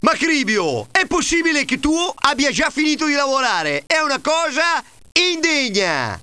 Sapete che basta cambiare le musiche di avvio ed arresto di windows per ottenere già un miglioramento apprezzabile?